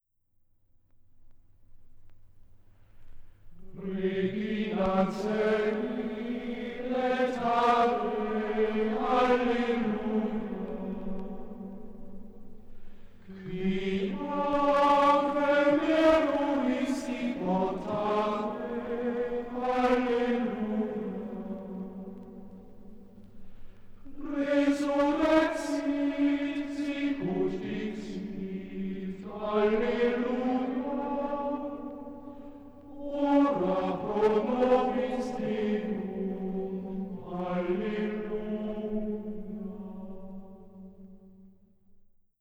Marianische Antiphone
Gesang: Schola gregoriana im Auftrag der Robert-Schumann-Hochschule Düsseldorf
aufgenommen in der Klosterkirche Knechtsteden